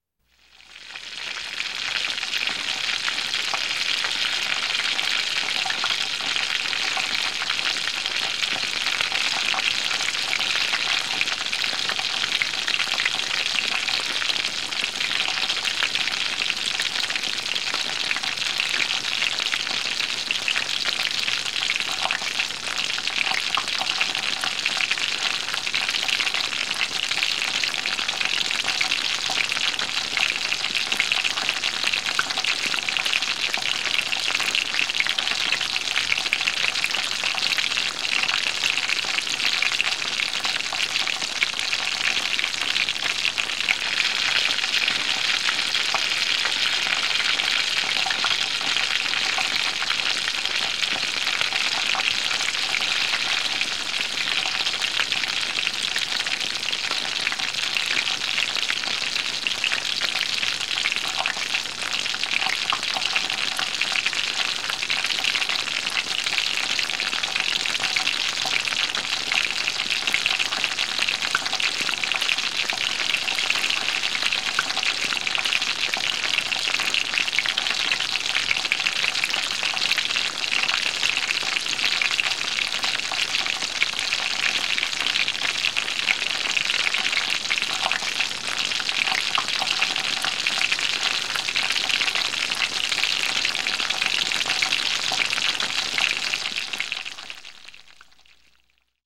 cooking-sizzle